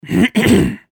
Gemafreie Sounds: Körpergeräusche
mf_SE-5893-clearing_ones_throat_1.mp3